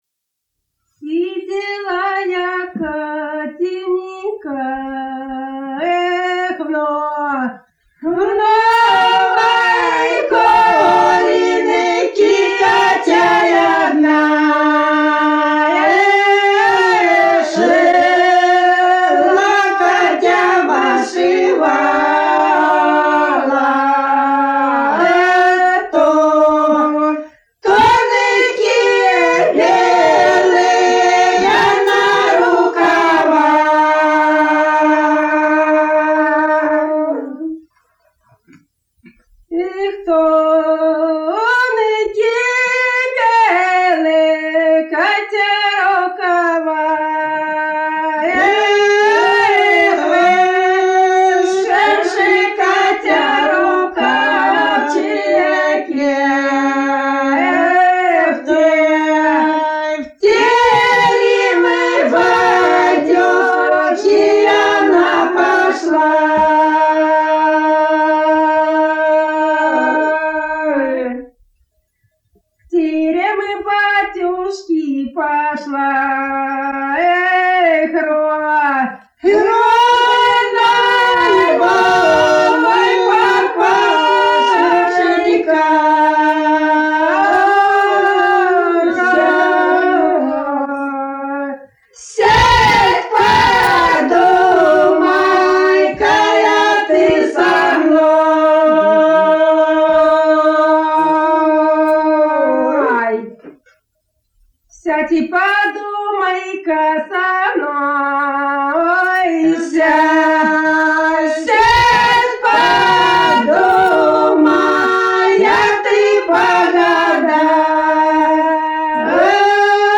Народные песни Касимовского района Рязанской области «Сидела я, Катенька», лирическая.